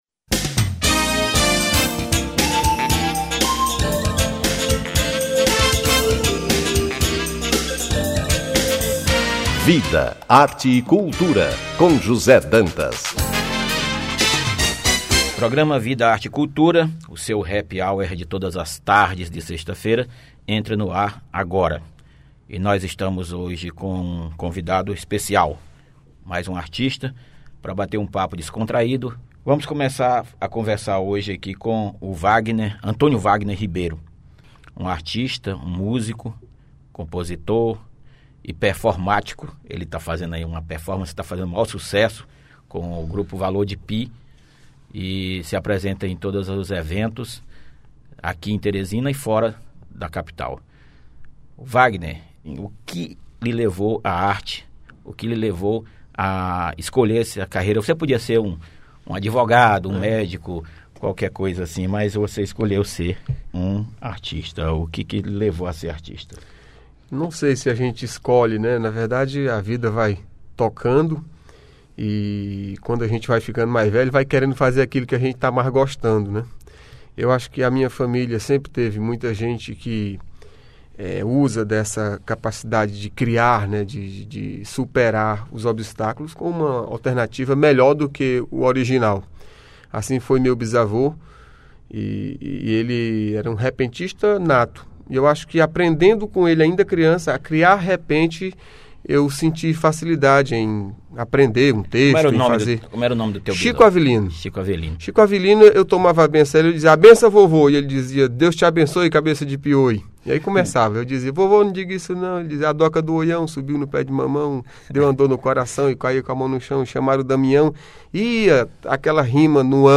Boi Bumbá
Violao Acústico 6
Cavaquinho
Clarinete
Percussão
Baixo Elétrico 6